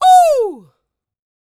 D-YELL 2501.wav